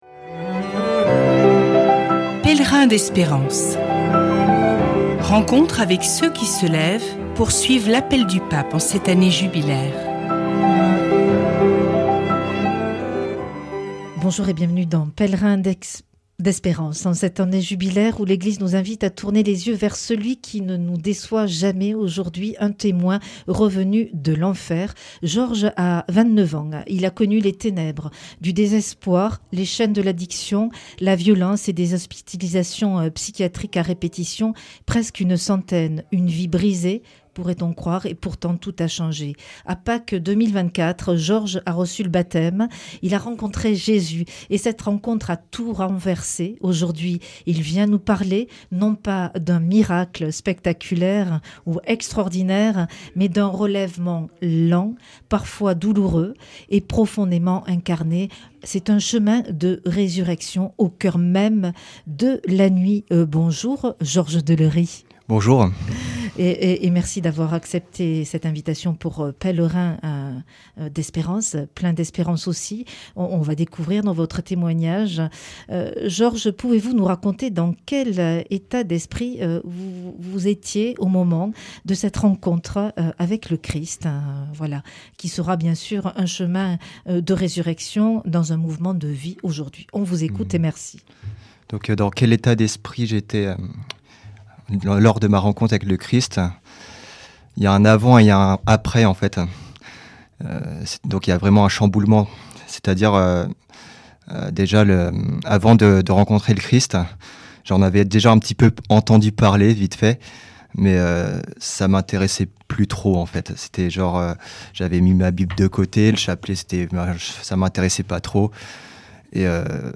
Accueil \ Emissions \ Foi \ Témoignages \ Pèlerins d’espérance \ J’étais mort, et me voici vivant !